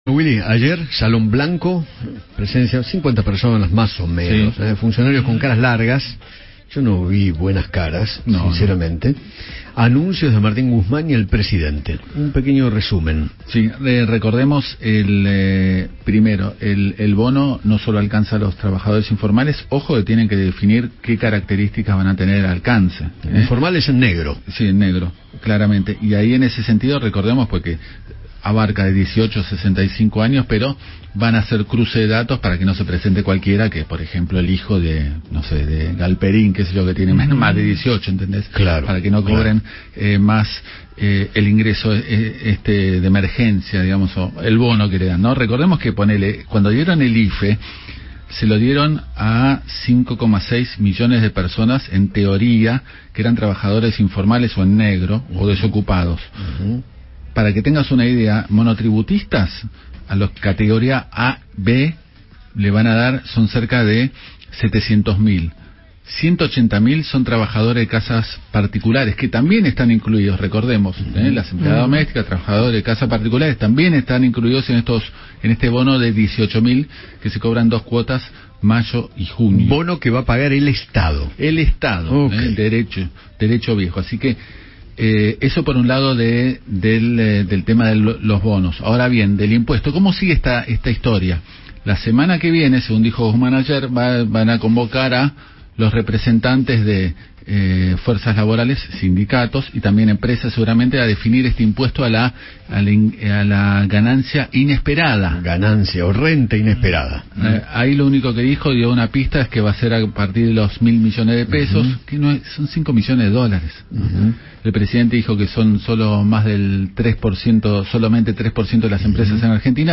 Daniel Funes de Rioja, titular de la Unión Industrial Argentina (UIA), habló con Eduardo Feinmann sobre el anuncio de ayer de Martín Guzmán y el impuesto a la “renta inesperada” que quiere imponer el Gobierno para pagar un bono extraordinario.